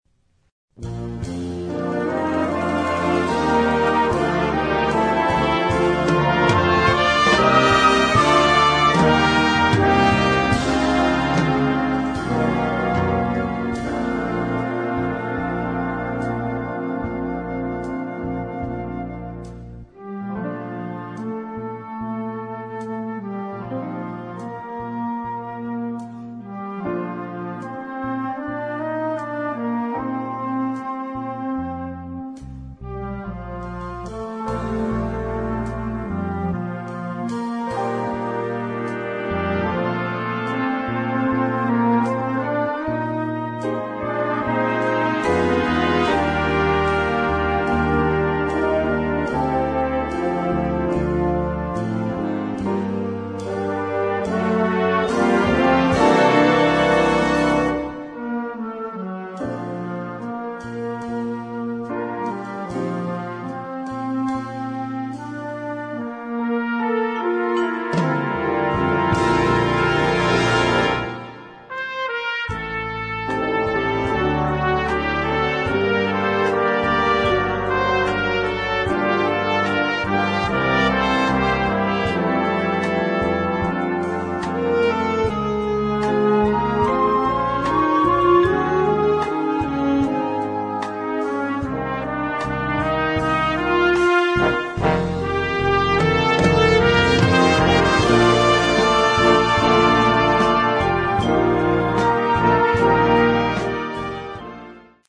rich, lush-sounding contemporary jazz ballad
Partitions pour orchestre d'harmonie des jeunes.